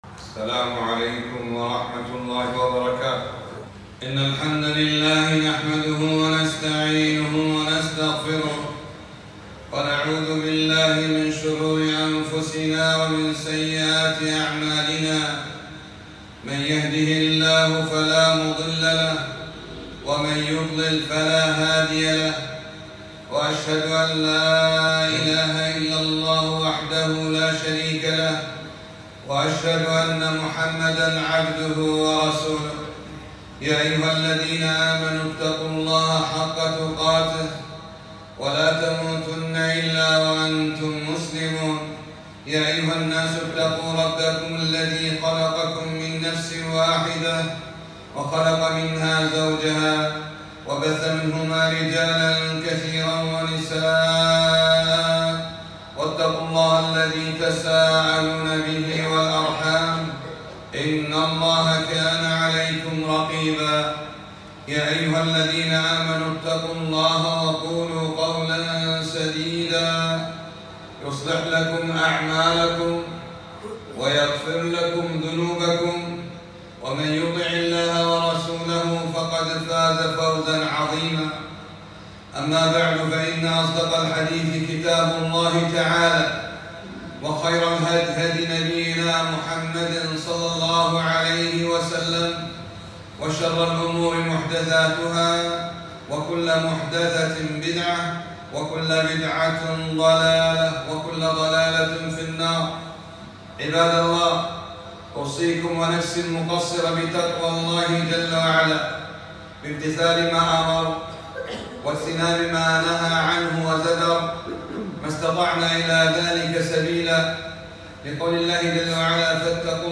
خطبة - (ولا تقربوا الزنا)